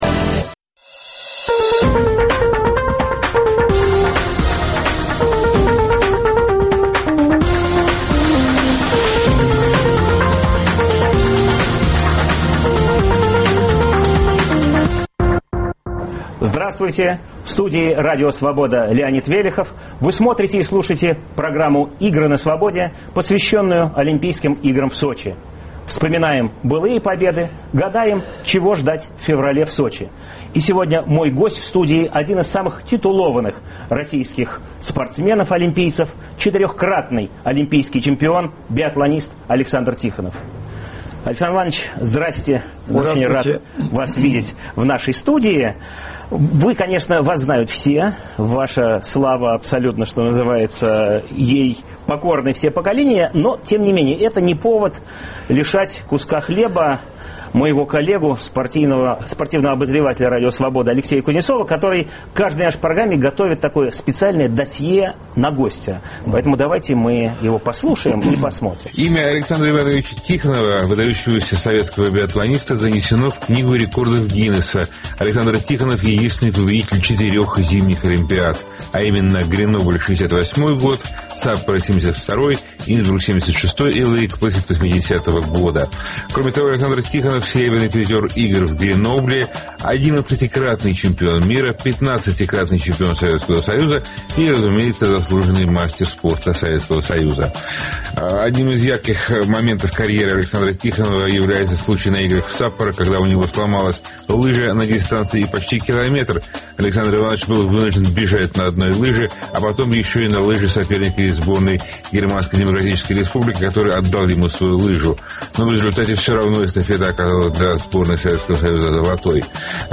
Александр Тихонов. Самый меткий лыжник России В эту субботу интервью биатлонистом, четырехкратным олимпийским чемпионом Александром Тихоновым.